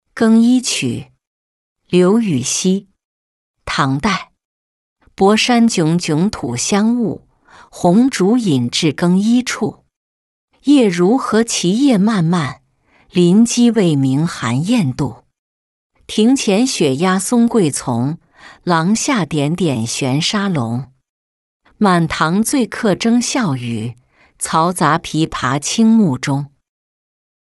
更衣曲-音频朗读